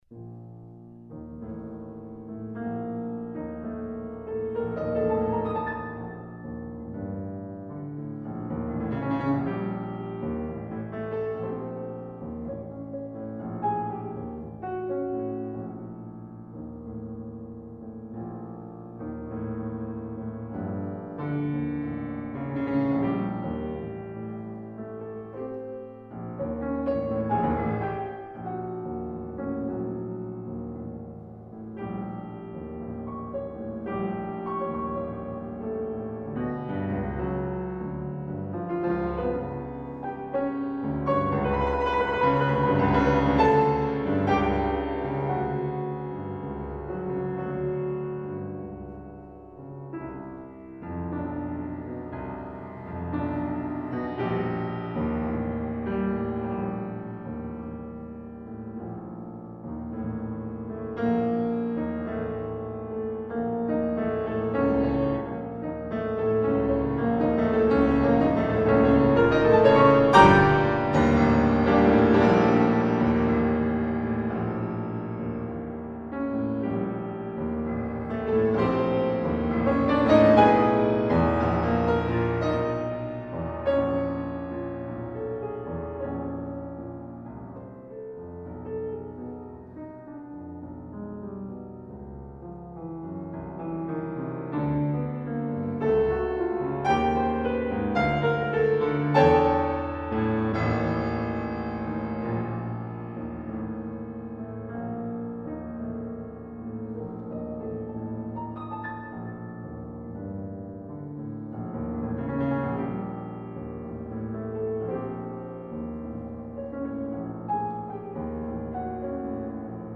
pianostukken
In het eerste deel van de landschappen van “de zee” (Jura, 1908) komen behalve veel chromatiek eveneens meer geavanceerde samenklanken voor. De toonsoort is onduidelijk, behalve helemaal op het einde.